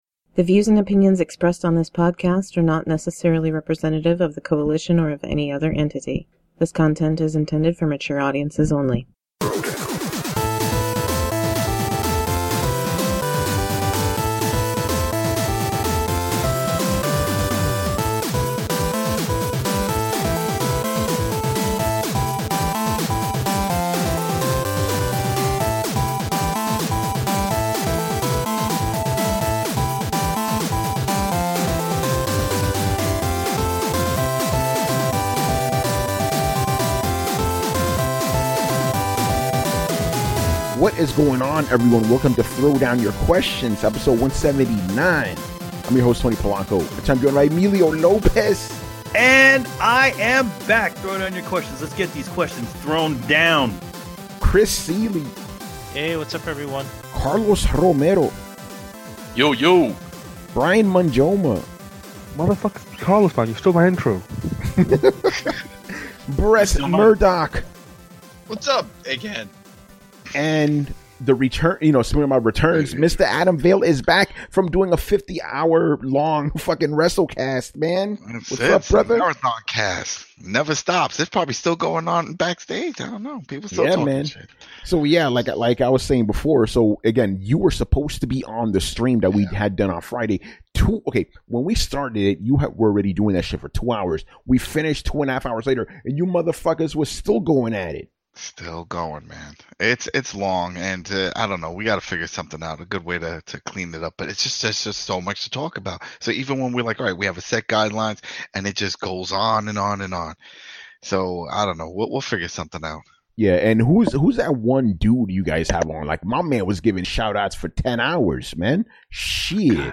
for our intro and outro music